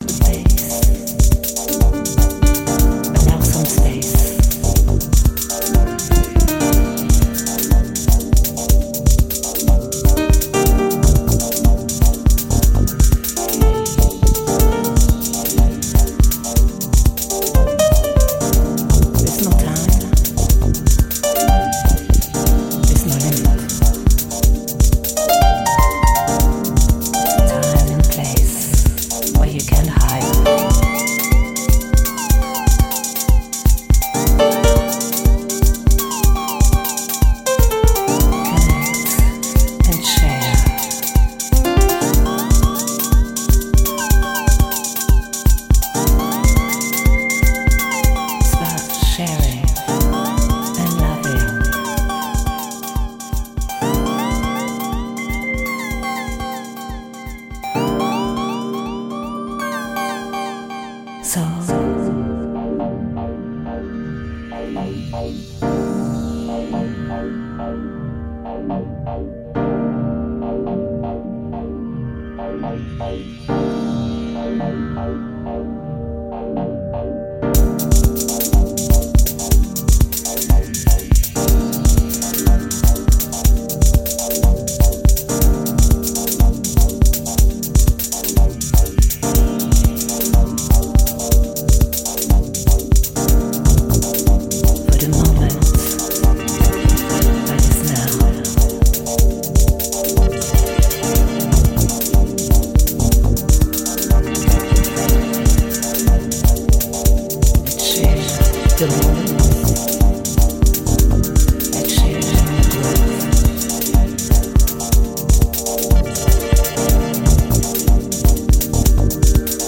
deep sounds and raw waves, perfect to floor